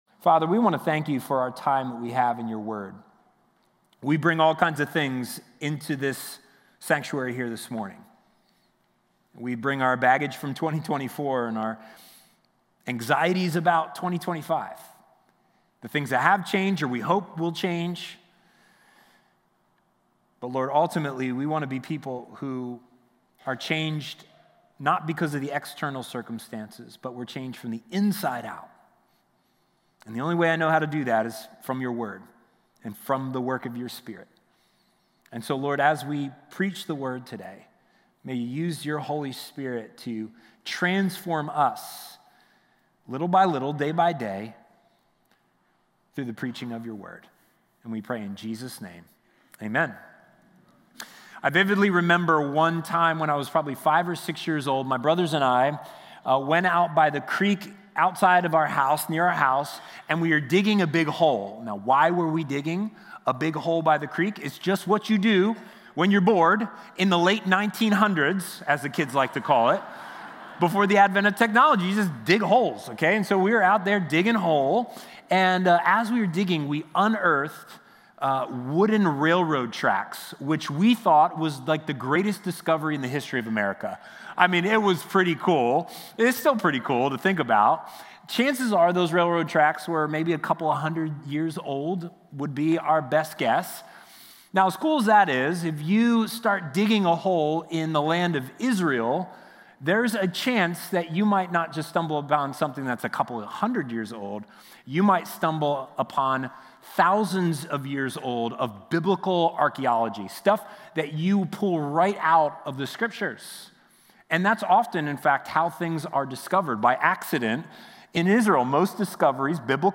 God-and-the-Underdogs-Sermon.mp3